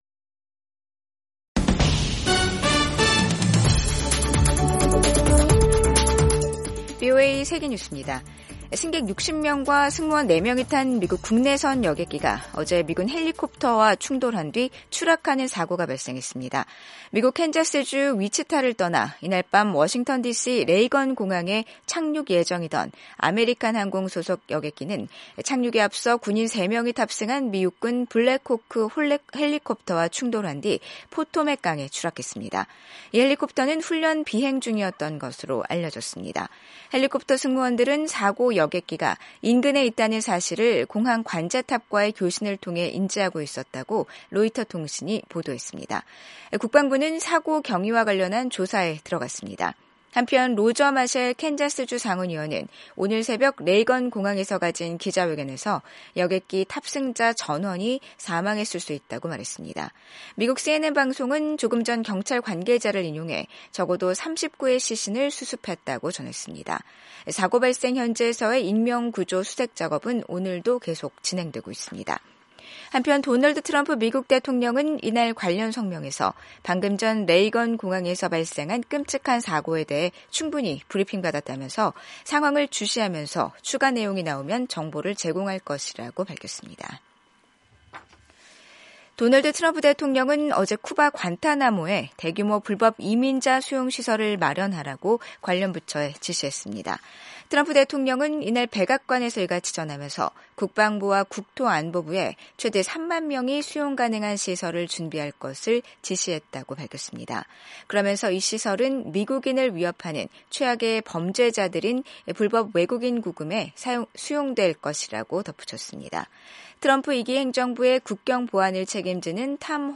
세계 뉴스와 함께 미국의 모든 것을 소개하는 '생방송 여기는 워싱턴입니다', 2025년 1월 30일 저녁 방송입니다. 이스라엘과 팔레스타인 무장 정파 하마스 간 휴전 협정의 일환으로 3차 인질∙포로 교환이 시작됐습니다. 미국 중앙은행인 연방준비제도(Fed)가 올해 첫 통화정책 회의에서 기준금리를 동결했습니다.